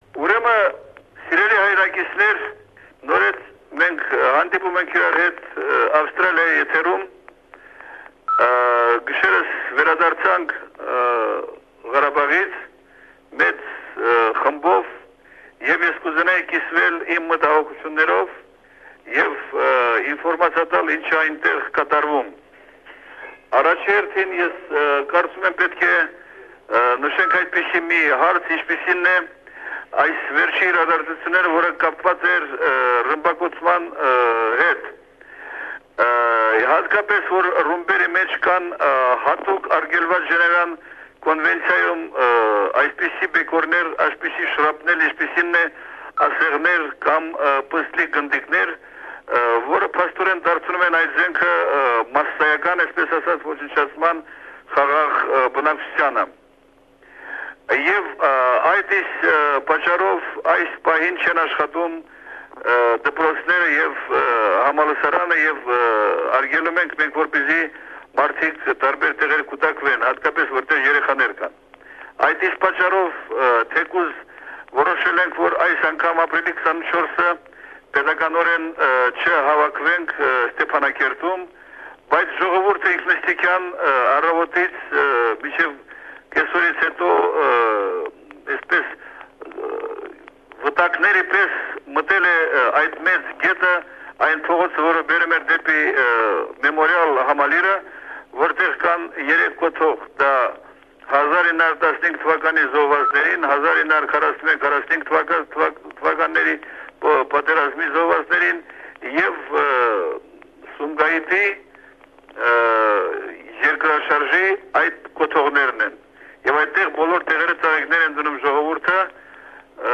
On the occasion of the 40th anniversary of SBS Radio Armenian Program, we bring you another segment broadcast 22 years ago. Zori Balayan, doctor, novelist and journalist explains why, a month before the 1994 ceasefire, all schools and the university in Stepanakert were closed, public gatherings forbidden and the government decided officially not to commemorate the genocide on April 24.